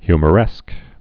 (hymə-rĕsk)